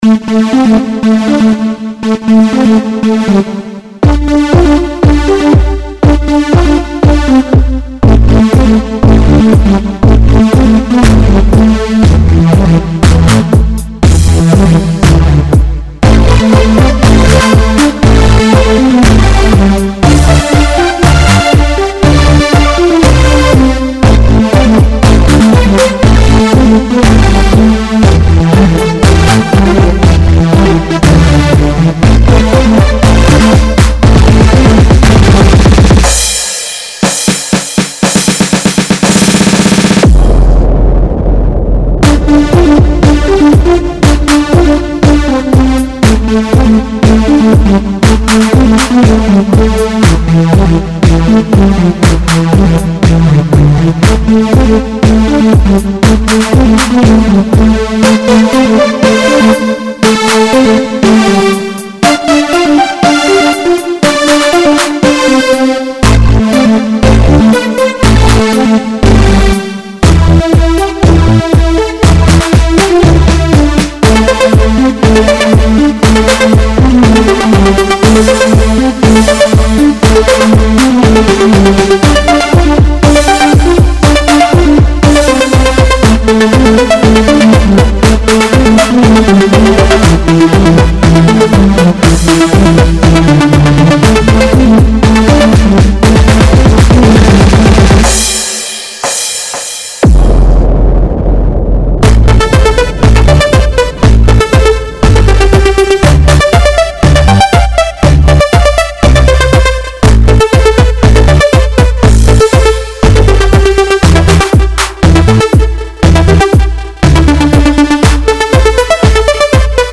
and consider upgrading to a web browser that supports HTML5 video Fashionized This song I was just experimenting with making my own instruments. I created them in Fruity Loops.